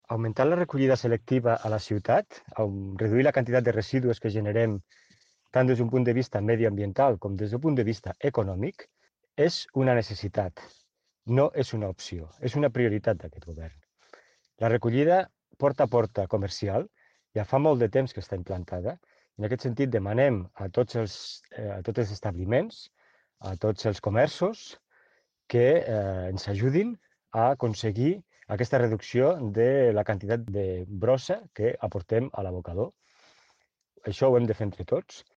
tall-de-veu-del-tinent-dalcalde-sergi-talamonte-sobre-la-recollida-selectiva-a-la-cituat